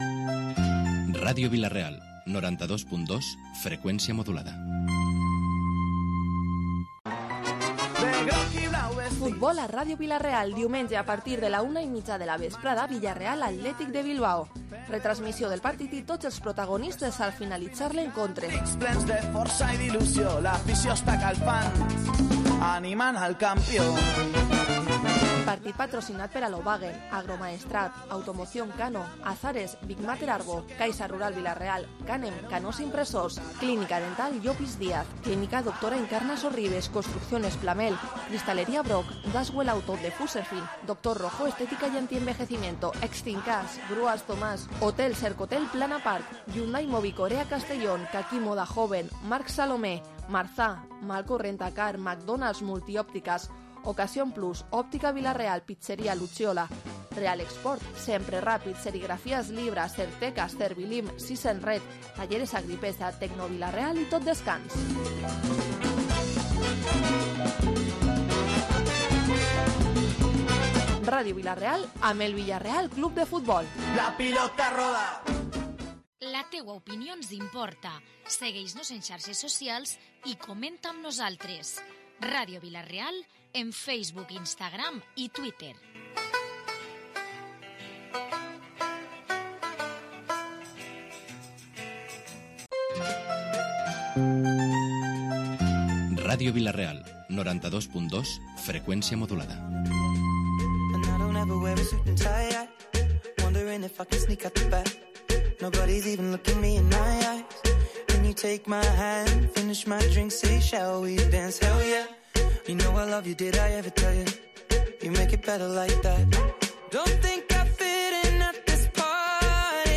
Visitas: 47 Hoy en Protagonistes hemos conocido más detalles sobre el Mercat Medieval que se instala este fin de semana en Vila-real con motivo de las Fiestas Fundacionales así como del proyecto impulsado por la Cooperativa Católico Agraria que, en colaboración con el Ayuntamiento de Vila-real, creará un insectario en la localidad para combatir las plagas en el campo de forma natural. Y t ertulia política con Javier Serralvo (PSPV), Alejo Font de Mora (PP), Maties Marin (Compromís), Natalia Gil (Ciudadanos), Paco Ibáñez (Vox) y Alejandro Moreno Sandoval, (Unides Podem) sobre las medidas anunciadas por el Gobierno central para el sector primario y sobre la expansión del coronavirus.